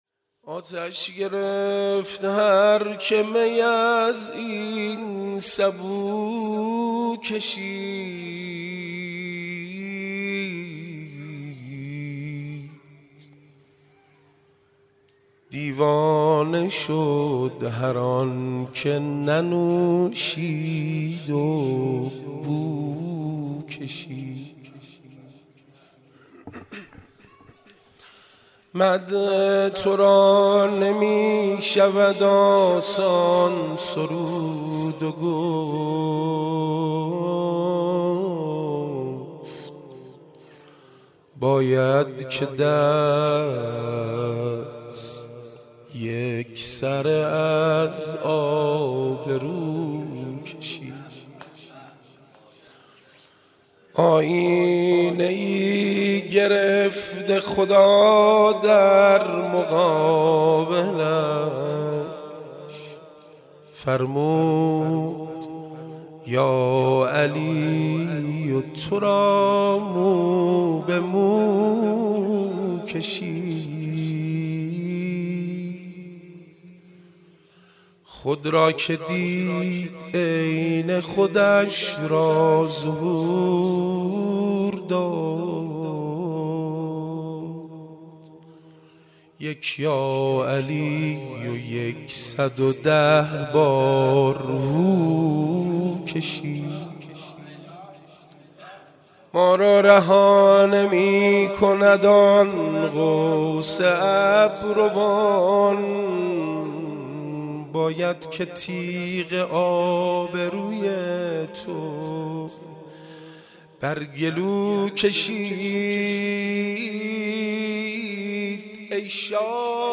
مدح حضرت امیرالمومنین (ع) - جلسه هفتگی جمعه 25 مرداد 1398